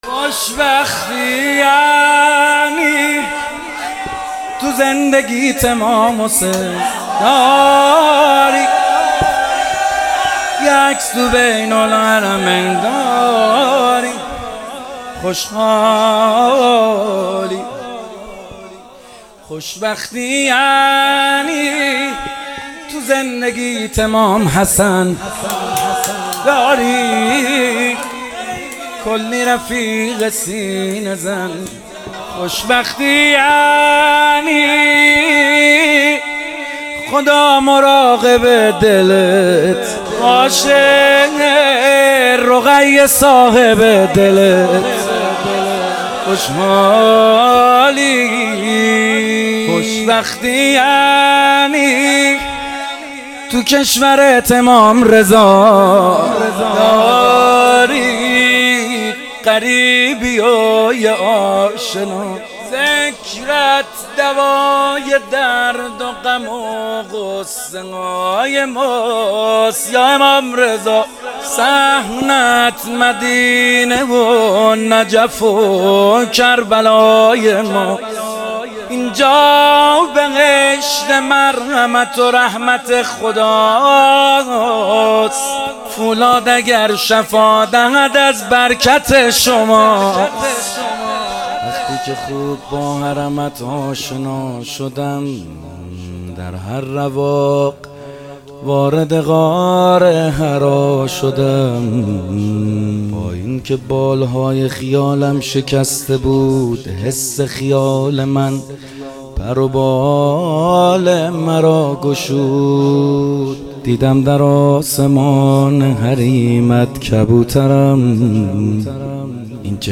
خوشبختی محمدحسین حدادیان | میلاد حضرت زهرا (س) | پلان 3